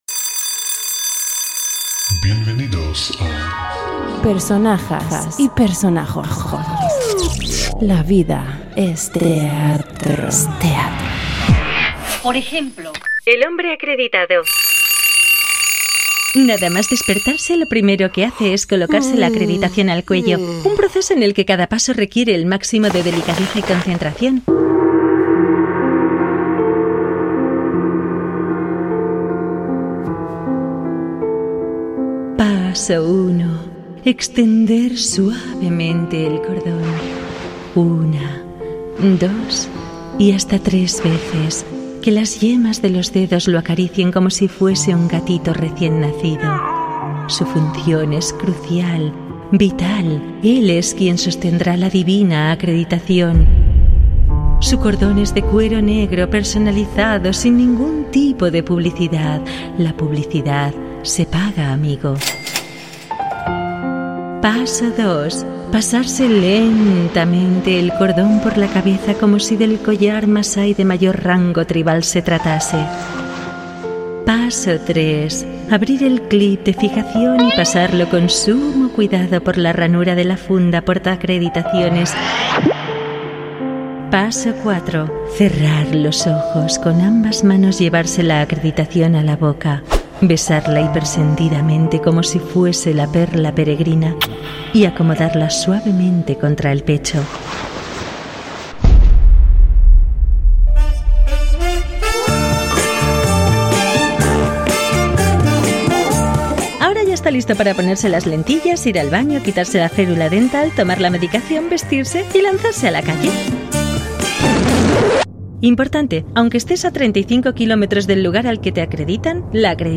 Audio: Ficción Sonora Personajas y personajes